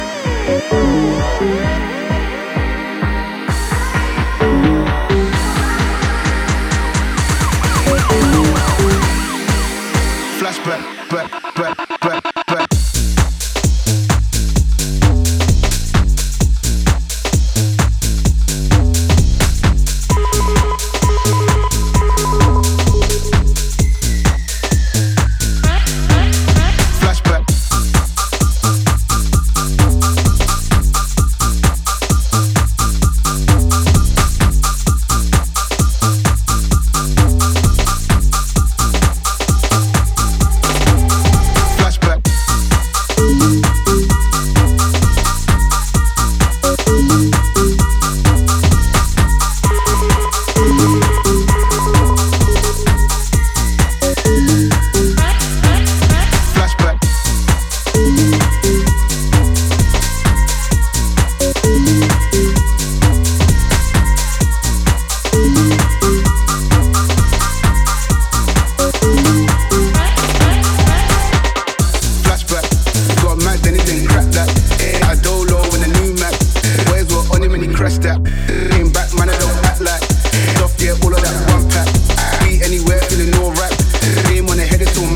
serving up a killer cut of bumping 4x4 UKG goodness!